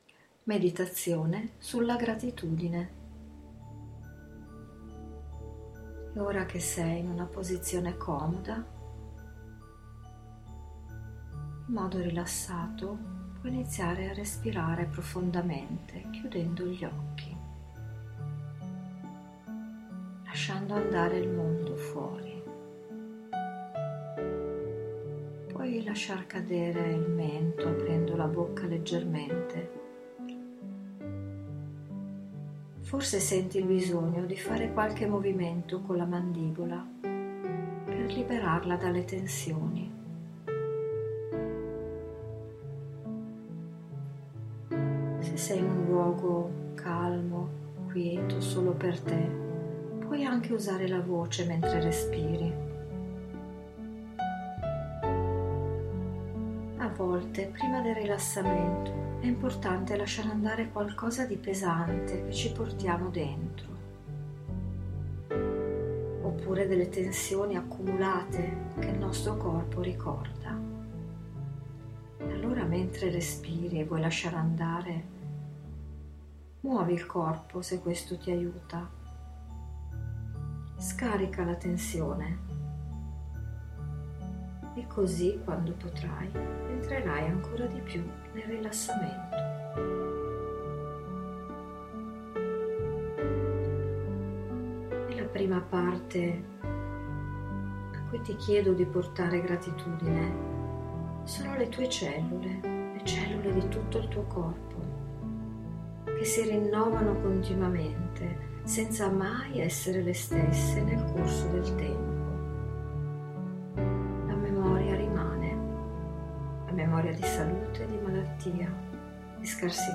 Meditazione guidata sulla Gratitudine - Felice Trasformazione Personale